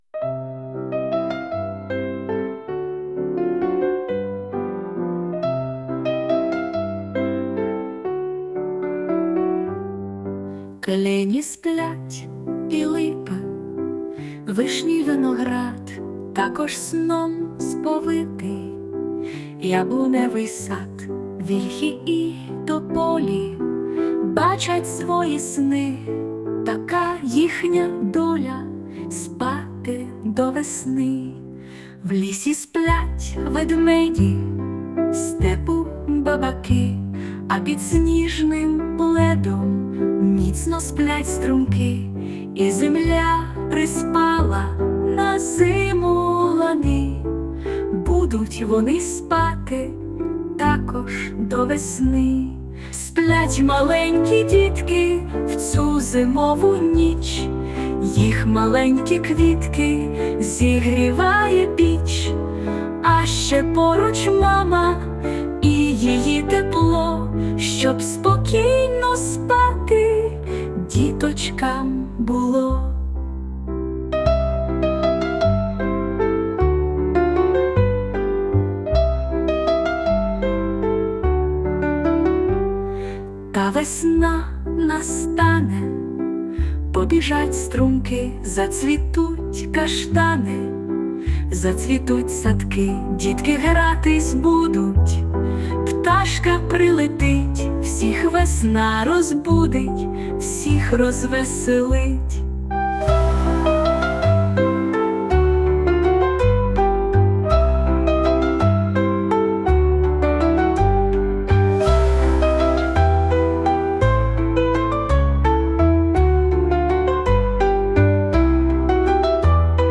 ЗИМОВИЙ СОН (колискова)